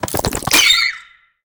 Sfx_creature_penguin_skweak_10.ogg